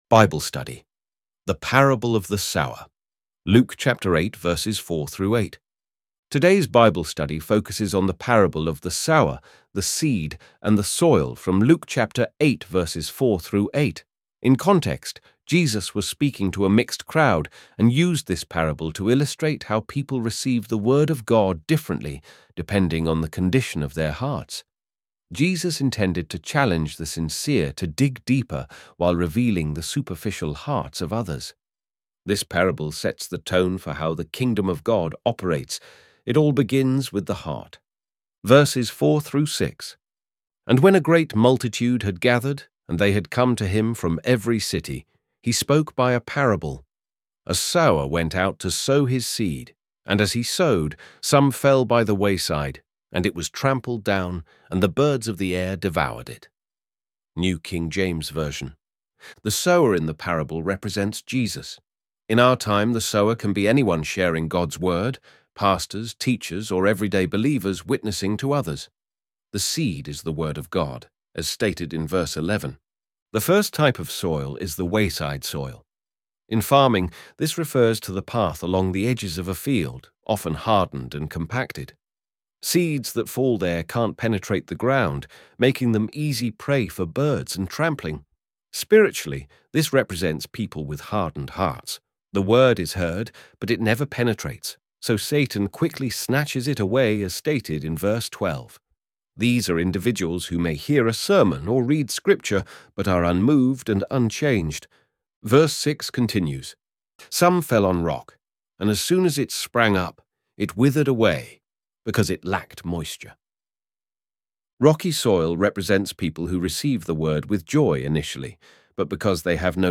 ElevenLabs_lu.mp3